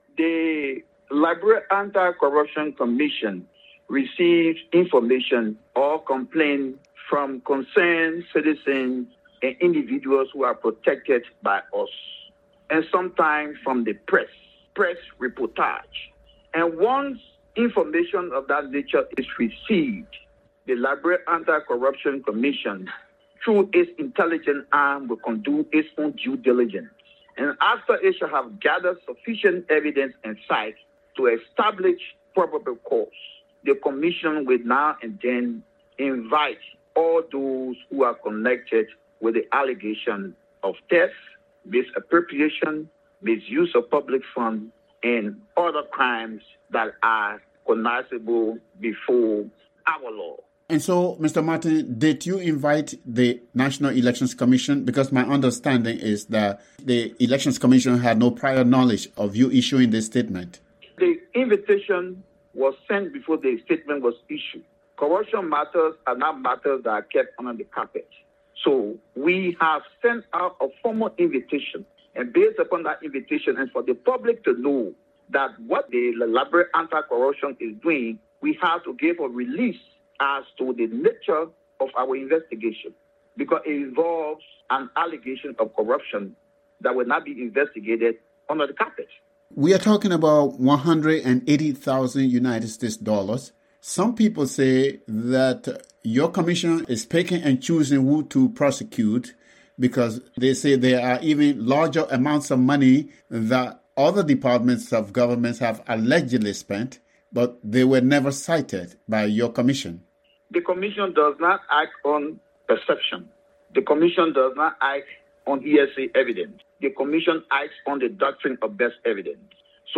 spoke with anti-corruption chairman Edwin Martin about the investigation.